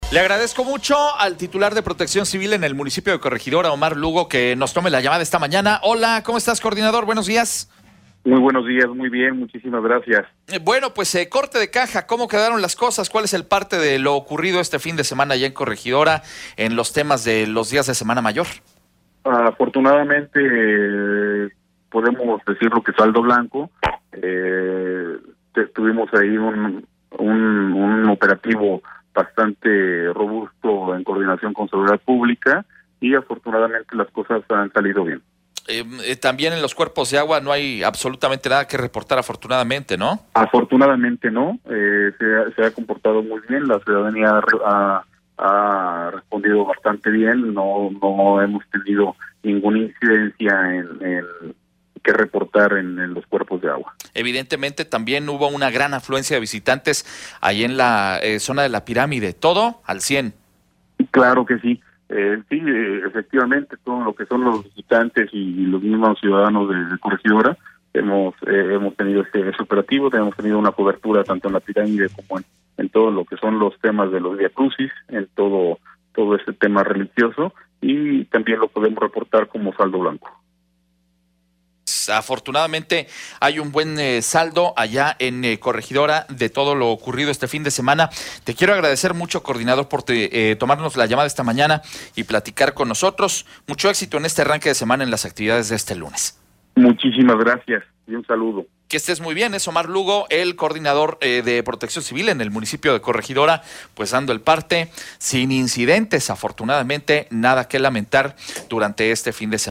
Entrevista con Omar Lugo, Director de Protección Civil del Mpio. de Corregidora